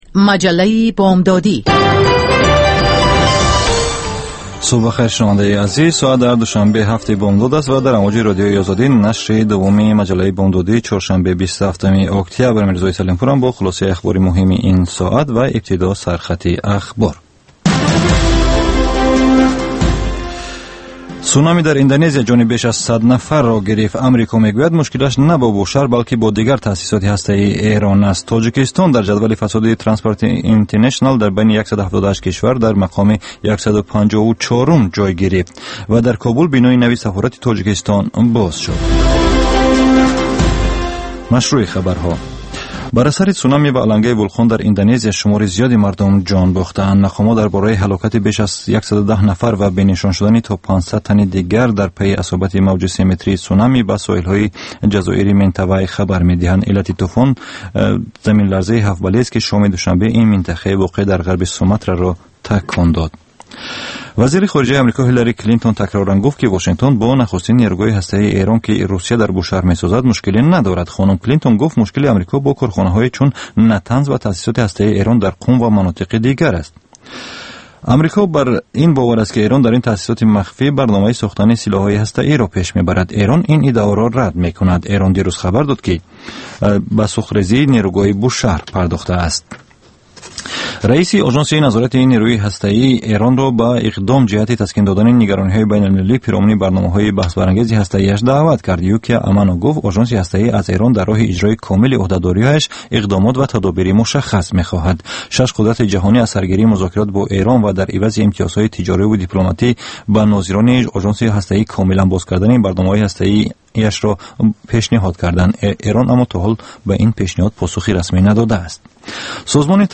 Дар маҷаллаи Дунёи иқтисод коршиносон, масъулони давлатӣ ва намояндагони созмонҳои марбутаи ғайридавлатию байналмилалӣ таҳаввулоти ахири иқтисоди кишварро баррасӣ мекунанд.